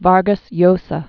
(värgəs yōsə, bärgäs yōsä), Mario Born 1936.